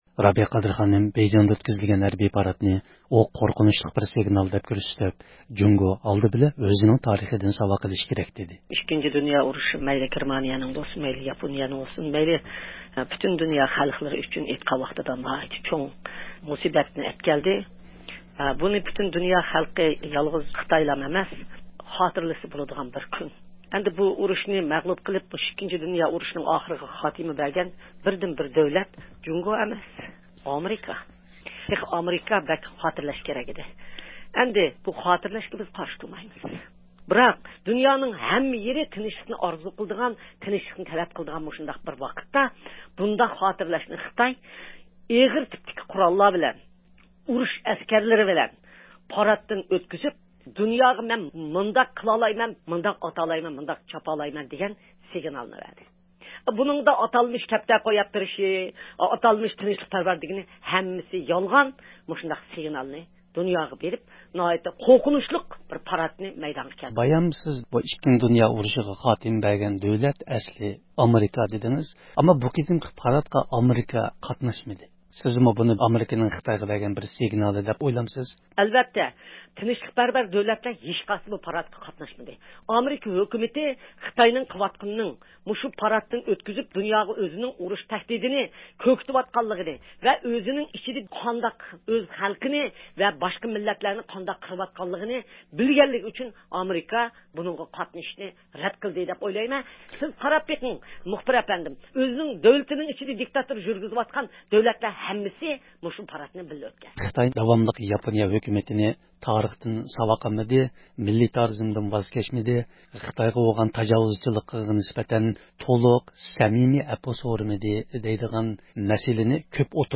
سۆھبىتىنىڭ